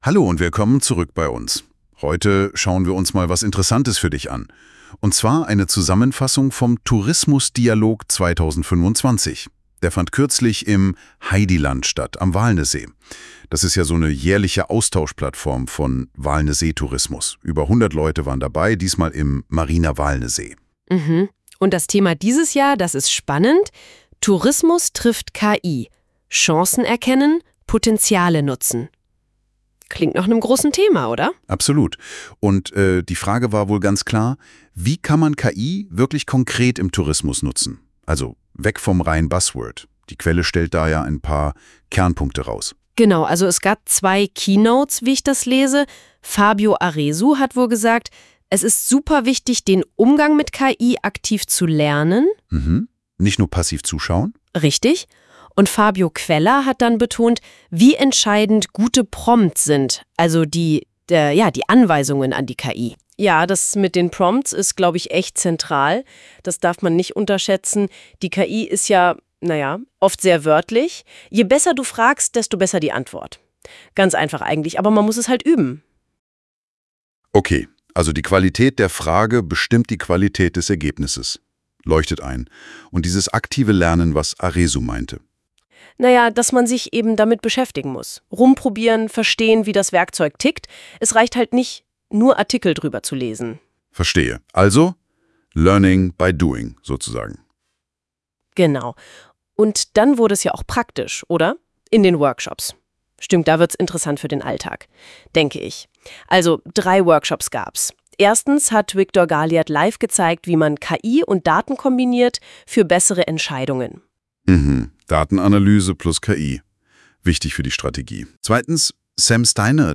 Erstmals wurde der Tourismus Dialog mit Hilfe von KI als
Podcast-Zusammenfassung aufgearbeitet. Die Inhalte wurden mittels KI erstellt – ein passender Abschluss für einen Anlass, der gezeigt hat, wie KI im Tourismus sinnvoll eingesetzt werden kann.